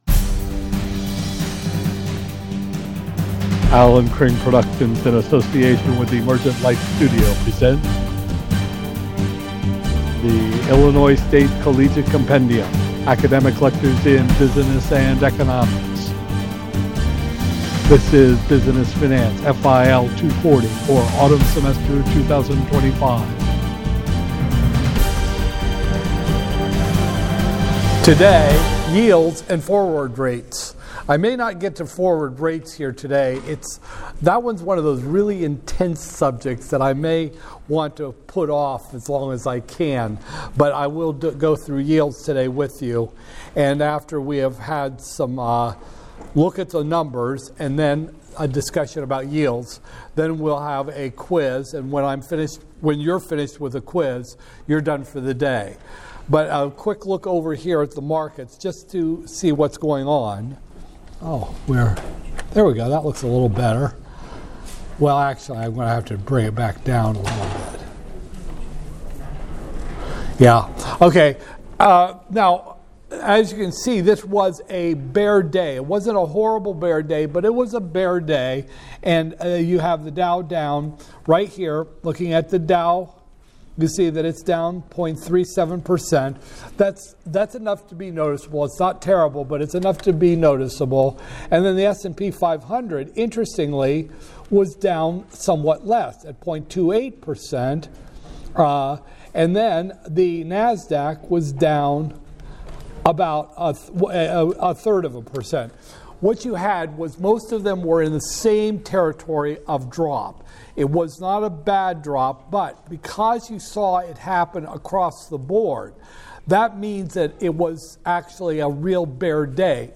Illinois State Collegiate Compendium is the podcasting platform for college lectures in business finance and economics.